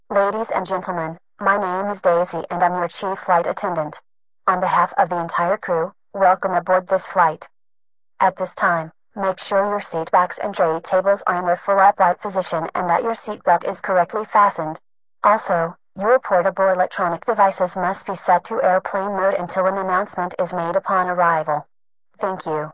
fa_welcome.wav